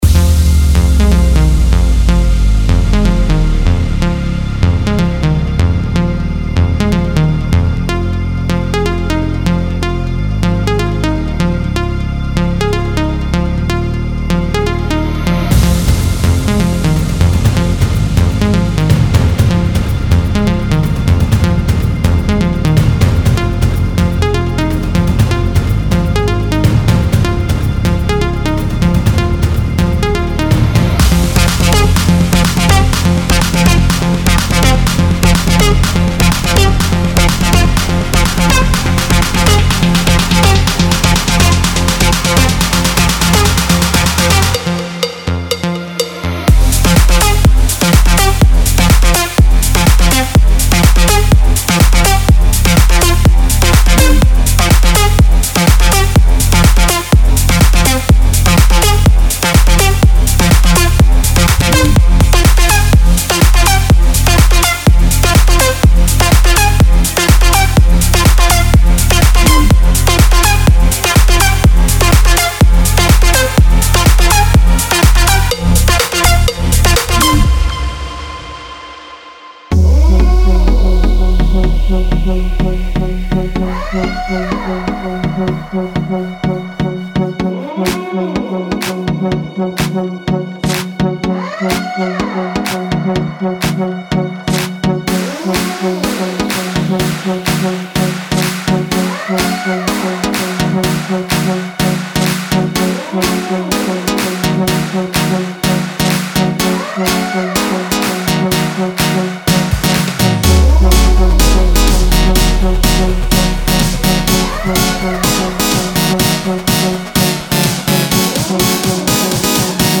3. EDM
期待一些欣欣向荣的和弦，钢琴和主音圈，沉重的低音线，完美均衡的鼓一次打击和循环，令人难
忘的人声循环以及令人敬畏的出色填充声音效果。